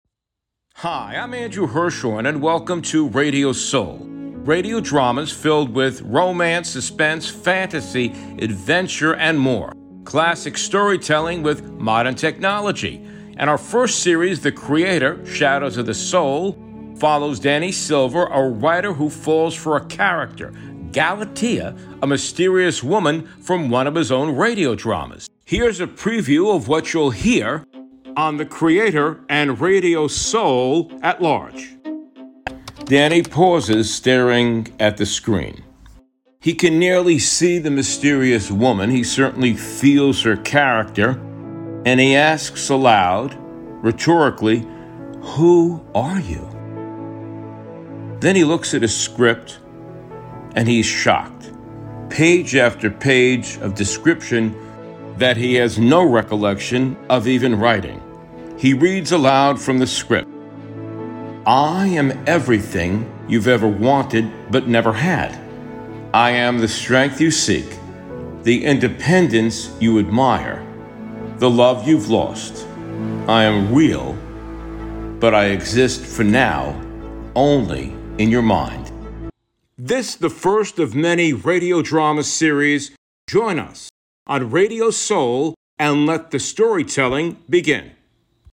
The Creator: Shadows of the Soul is a thrilling exploration of identity, obsession, and the power of creation, brought to life with immersive soundscapes, sound effects, haunting music, and powerful performances that echo through the shadows of the soul.